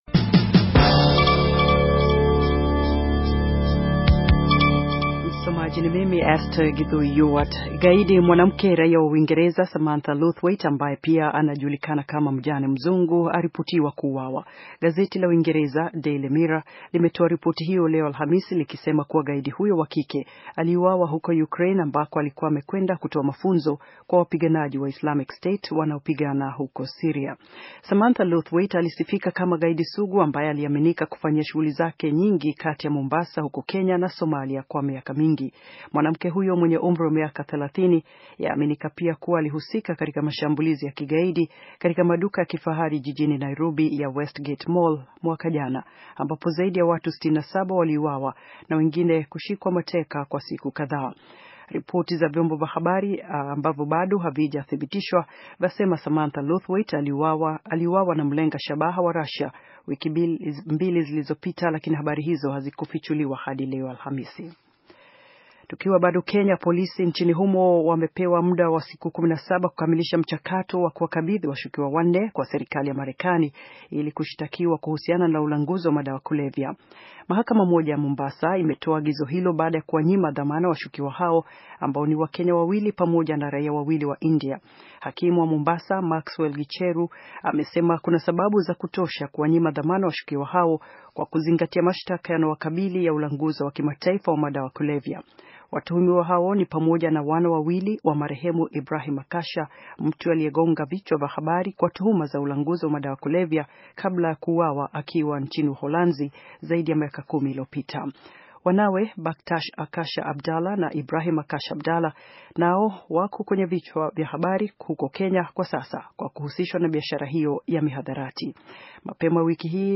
Taarifa ya habari - 6:16